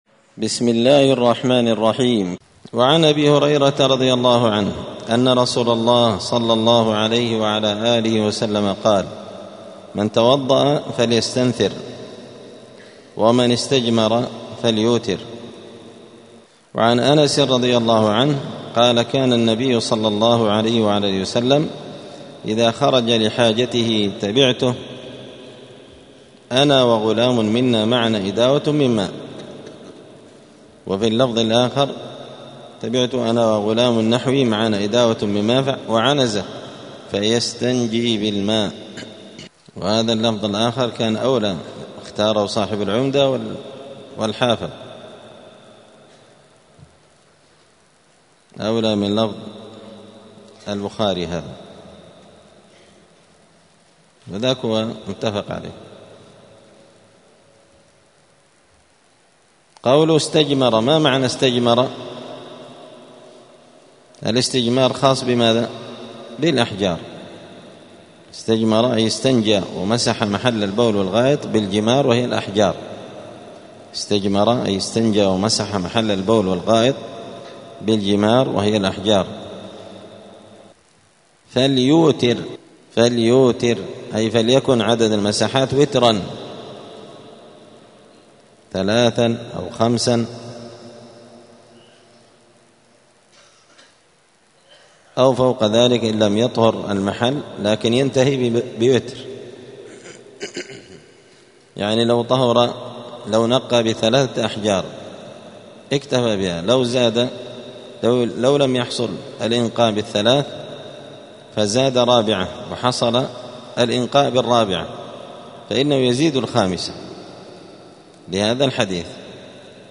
دار الحديث السلفية بمسجد الفرقان قشن المهرة اليمن
*الدرس الثامن والستون [68] {باب الاستطابة حكم الاستنجاء بأكثر من ثلاثة أحجار}*